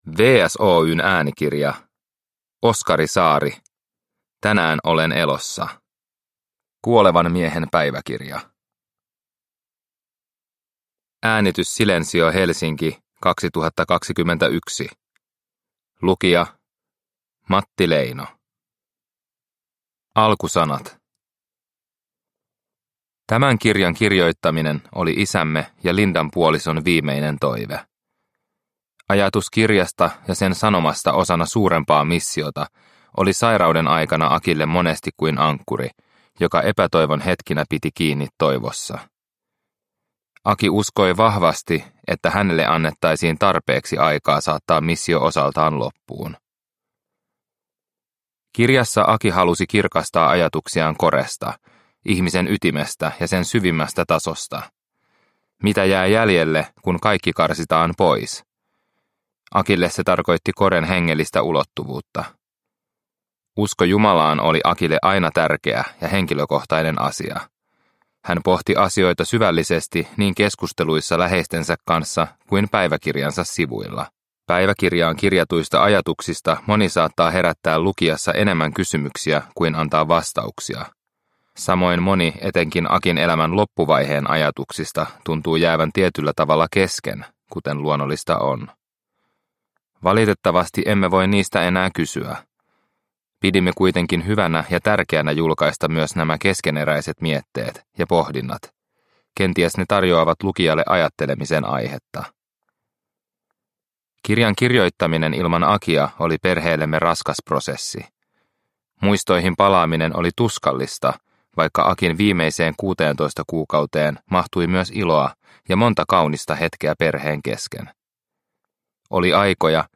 Tänään olen elossa – Ljudbok